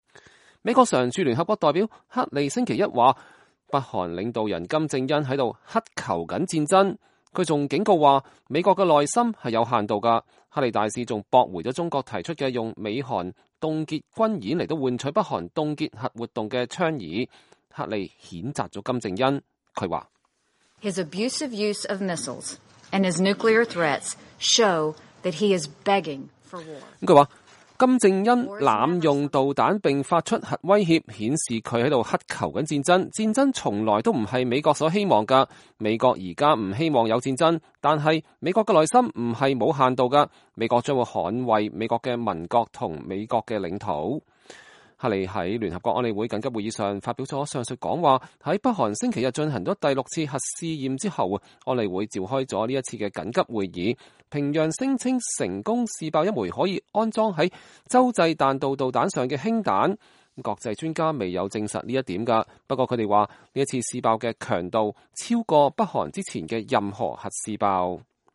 黑利大使在聯合國總部就北韓問題在聯合國安理會緊急會議上講話